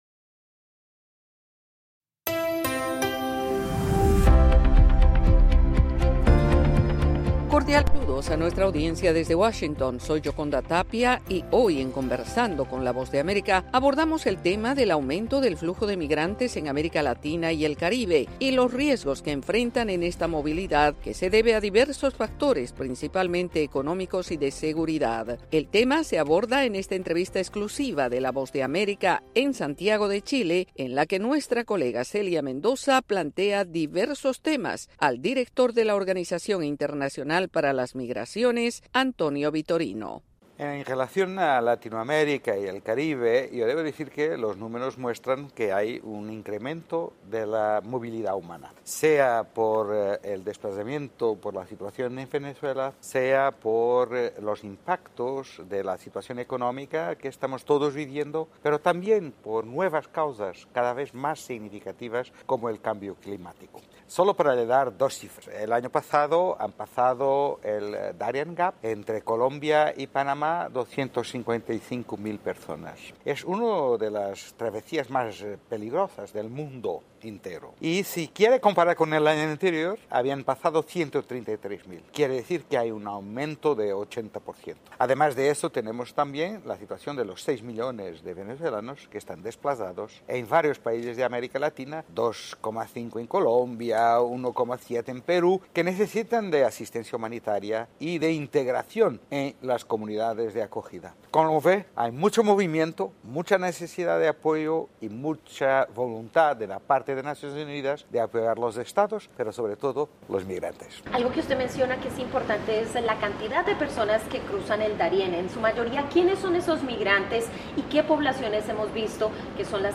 Conversamos con António Vitorino, director de la Organización Internacional para las Migraciones en entrevista exclusiva con la Voa de América destacando las condiciones de riesgo y vulnerabilidad de los migrantes.